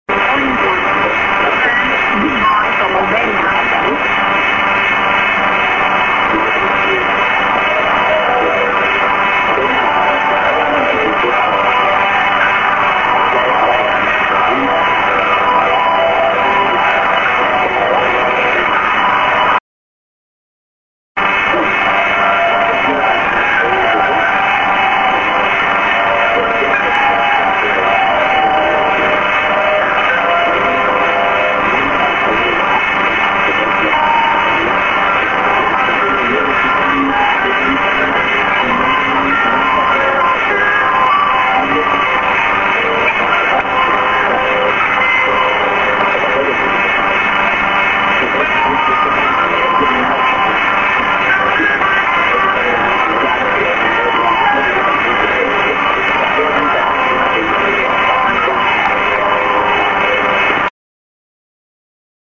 End ID:good by Albania(women)->IS